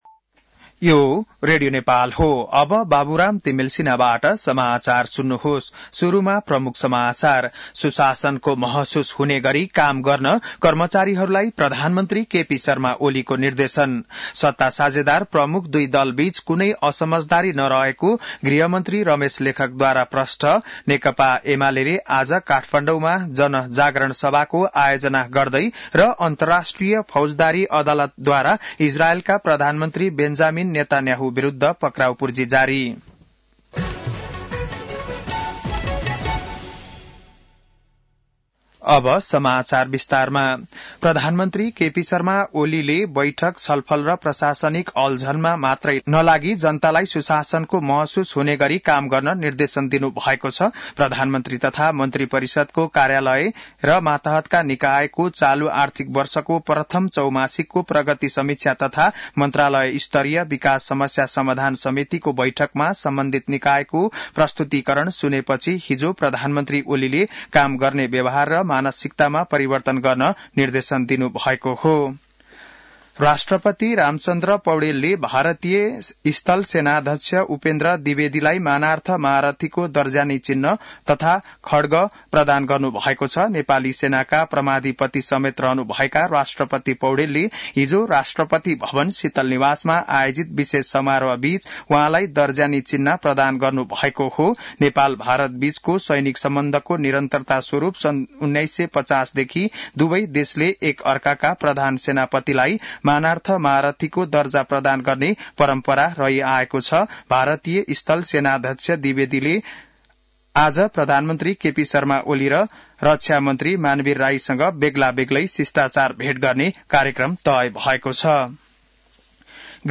बिहान ९ बजेको नेपाली समाचार : ८ मंसिर , २०८१